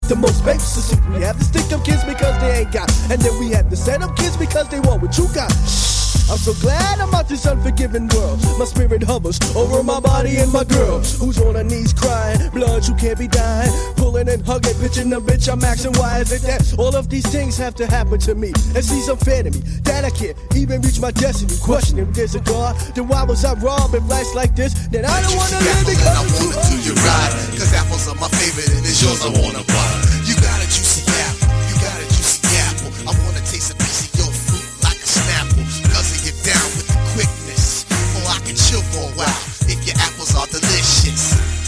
hardcore hip-hop/gangsta rap/r&b demo tape EP